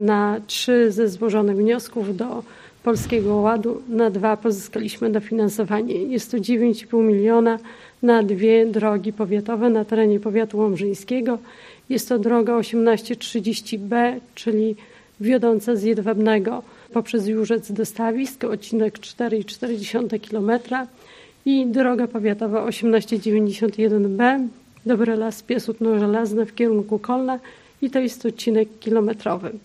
O charakterze inwestycji mówiła podczas konferencji prasowej Prezes zarządu, wicestarosta łomżyński, Maria Dziekońska: